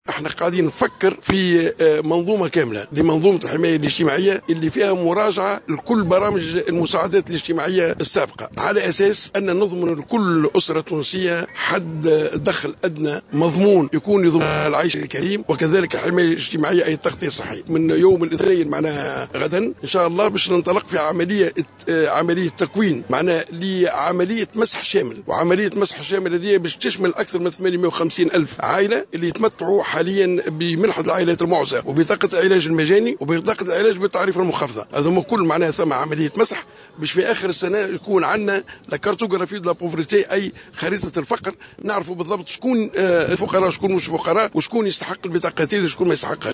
أعلن وزير الشؤون الاجتماعية أحمد عمار الينباعي اليوم الأحد 08 نوفمبر 2015 على هامش مشاركته في الاحتفال باليوم الوطني لعيد الشجرة بولاية المنستير أنه ستنطلق بداية من يوم غد الاثنين عملية مسح شامل للعائلات المعوزة.